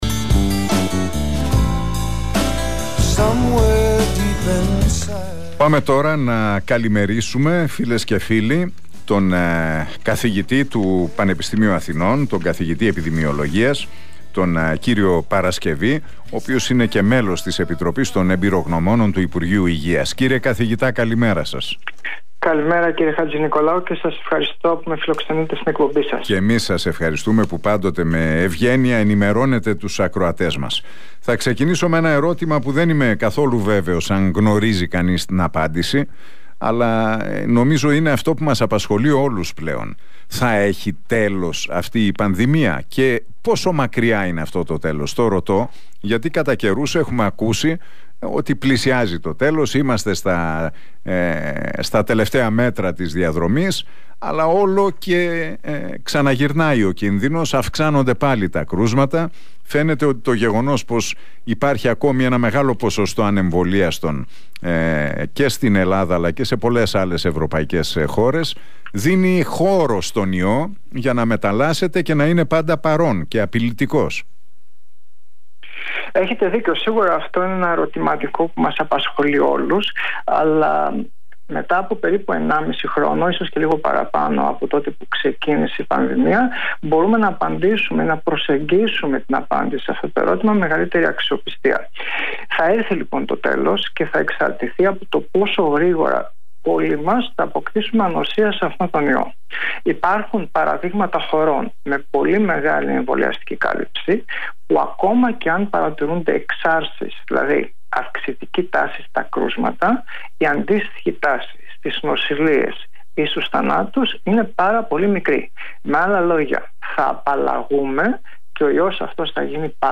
μιλώντας στον Realfm 97,8 και στην εκπομπή του Νίκου Χατζηνικολάου δήλωσε ότι “ύστερα από 1,5 χρόνο από τότε που ξεκίνησε η πανδημία